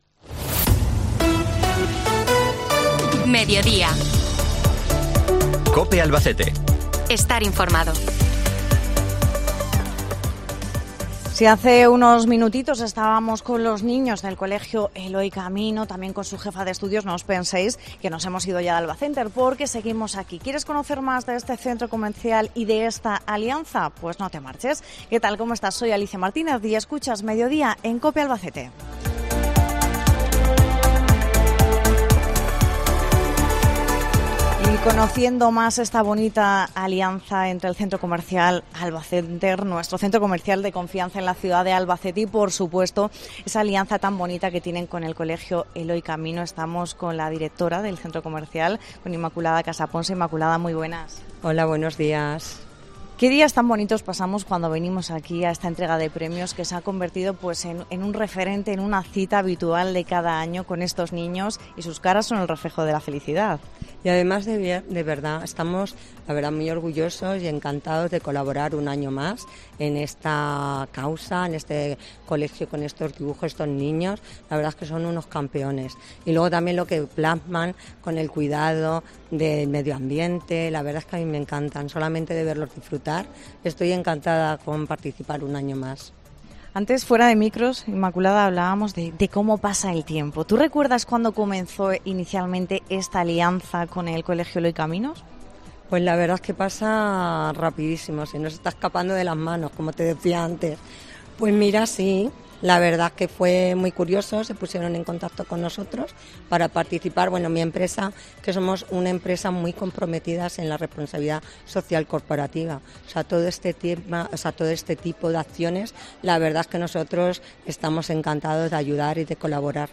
Hoy con un programa muy especial con los niños y niñas del CEE Eloy Camino desde Albacenter